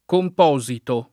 composito [ komp 0@ ito ] agg.